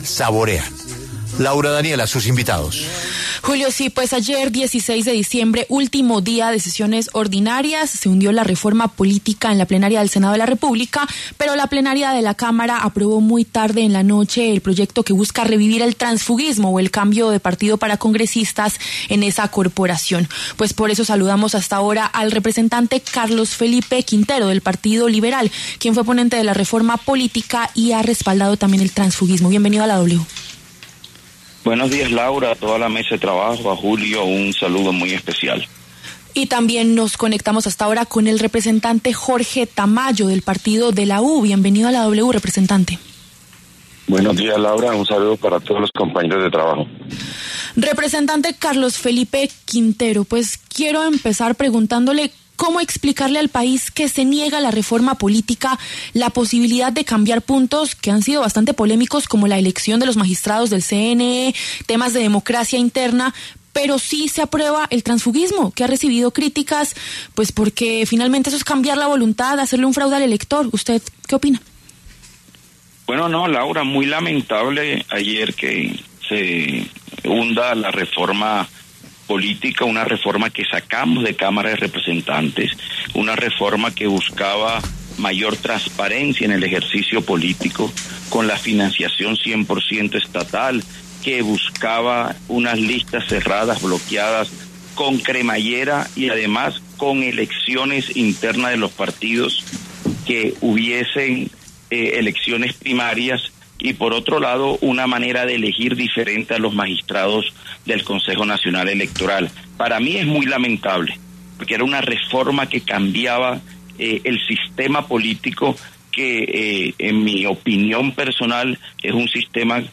Carlos Felipe Quintero, representante del Partido Liberal, y Jorge Tamayo, del Partido de la U, hablaron en los micrófonos de La W.